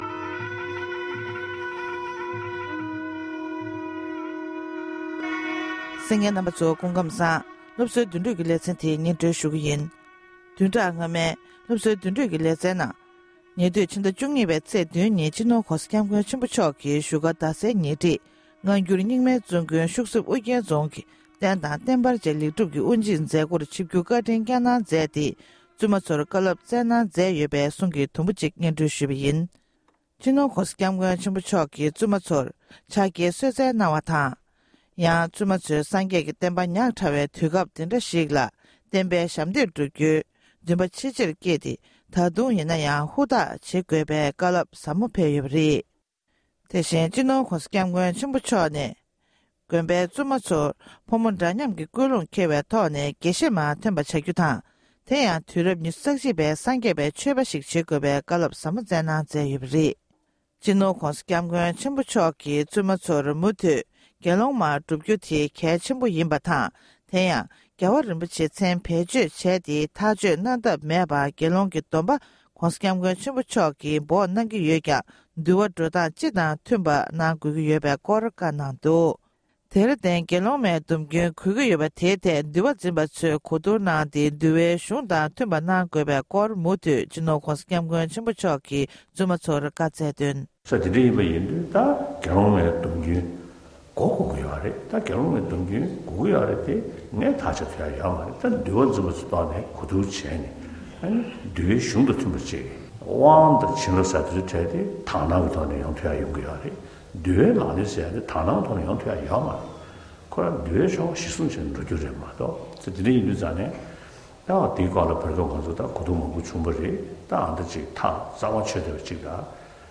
༸གོང་ས་མཆོག་ནས་བཙུན་དགོན་ཤུག་གསེབ་ཨོ་རྒྱན་རྫོང་དབུ་འབྱེད་མཛད་ཐོག་བཀའ་སློབ་བསྩལ་བ།